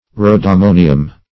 Search Result for " rhodammonium" : The Collaborative International Dictionary of English v.0.48: Rhodammonium \Rho`dam*mo"ni*um\, a. (Chem.)